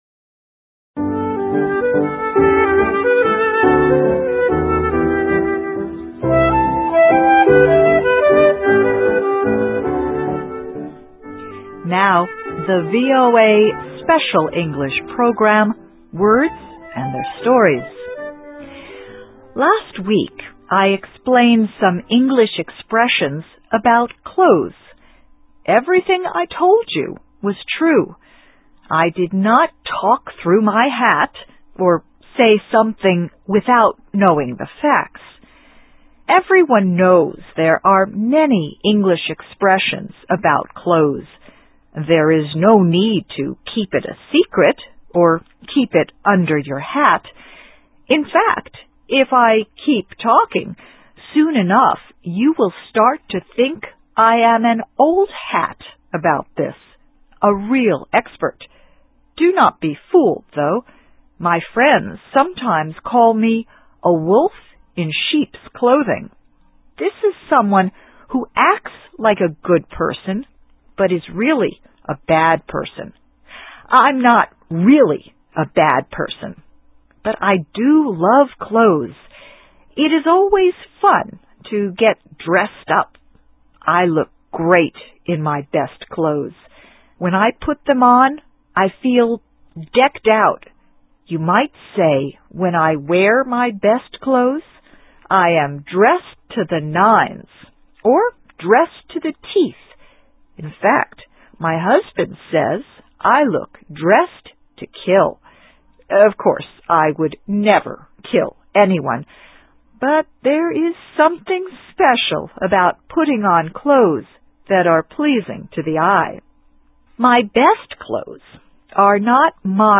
Words and Their Stories: Expressions About Clothes - Part 2 ... and Hats (VOA Special English 2007-07-09)